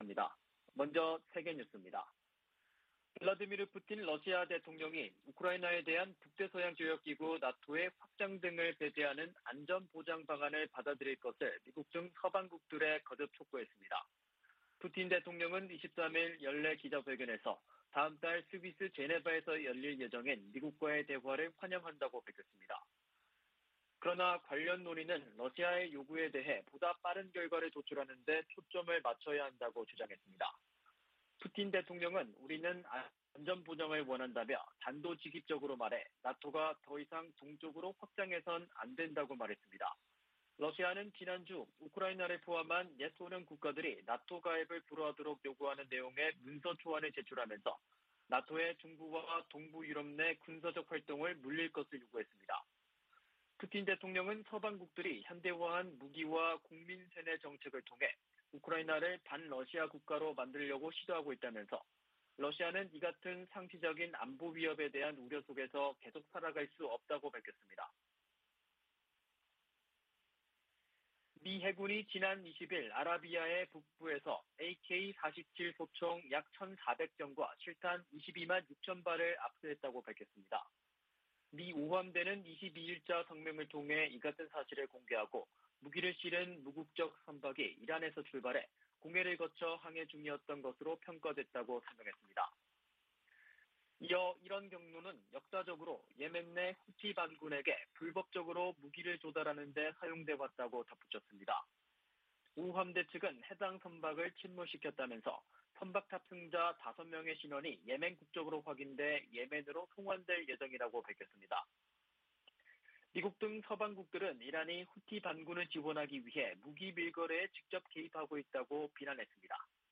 VOA 한국어 '출발 뉴스 쇼', 2021년 12월 24일 방송입니다. 미 의회가 올해 처리한 한반도 외교안보 관련안건은 단 한 건이며, 나머지는 내년으로 이월될 예정입니다. 미국과 한국의 연합군사훈련 시기를 포함한 모든 결정은 양국 간 합의로 이뤄질 것이라고 미 국방부가 밝혔습니다. 올해 국제사회의 대북 지원 모금 실적이 지난 10년 새 최저 수준으로 나타났습니다.